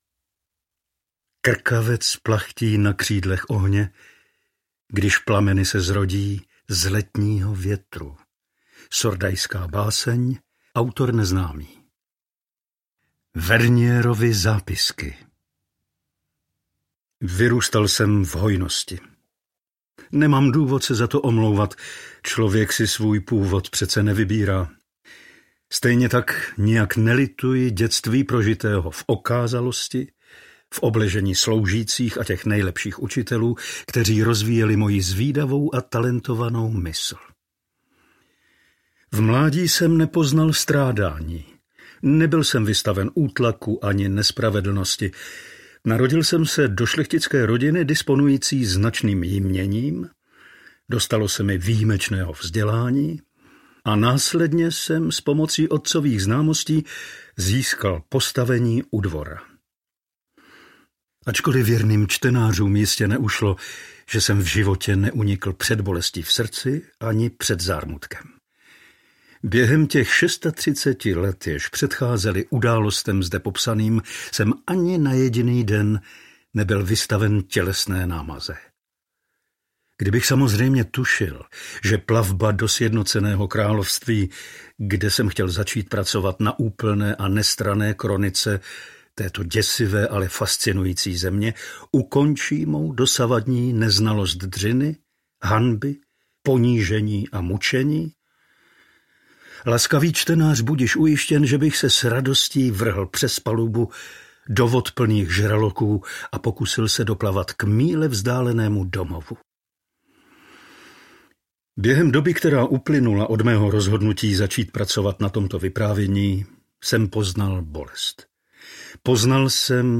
Pán věže audiokniha
Ukázka z knihy